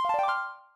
get-bonus2.ogg